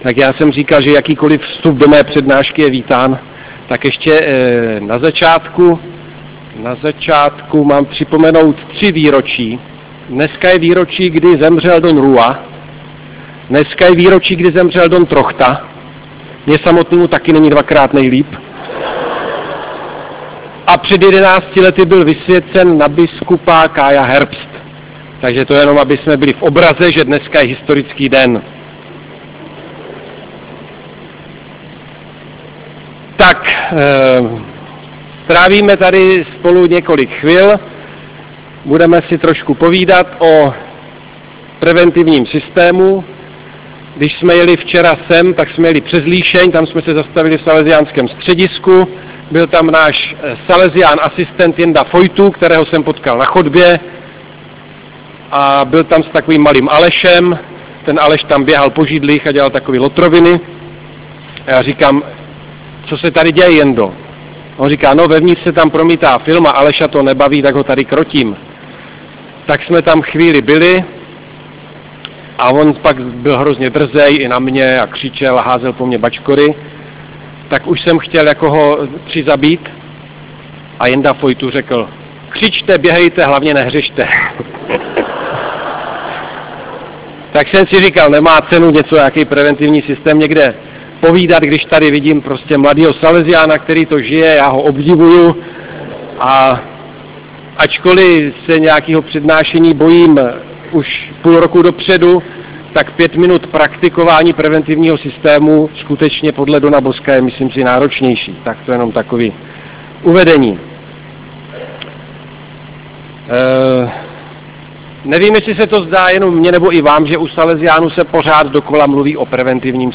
Nahrávky z 8. Dnů salesiánské spirituality v Kroměříži